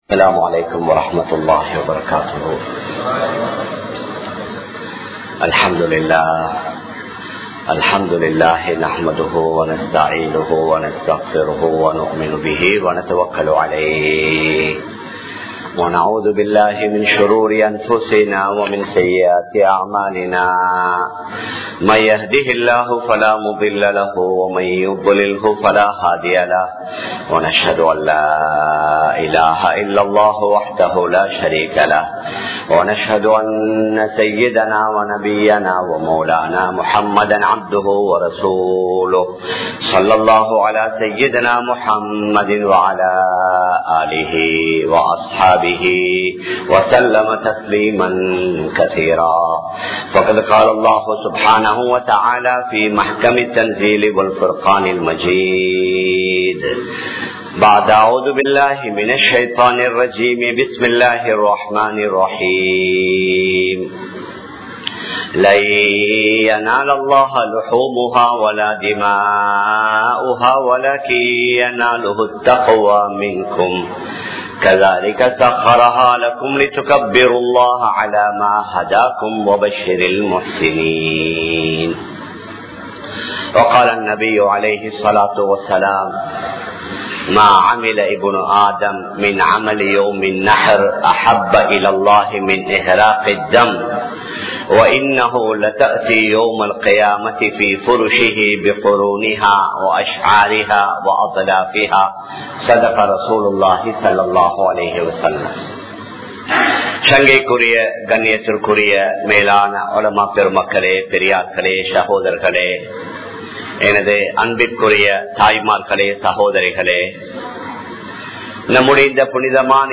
Perunaal Thina Amalhal (பெருநாள் தின அமல்கள்) | Audio Bayans | All Ceylon Muslim Youth Community | Addalaichenai
Muhiyadeen Jumua Masjith